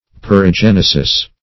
perigenesis - definition of perigenesis - synonyms, pronunciation, spelling from Free Dictionary
Search Result for " perigenesis" : The Collaborative International Dictionary of English v.0.48: Perigenesis \Per`i*gen"e*sis\, n. (Biol.) A theory which explains inheritance by the transmission of the type of growth force possessed by one generation to another.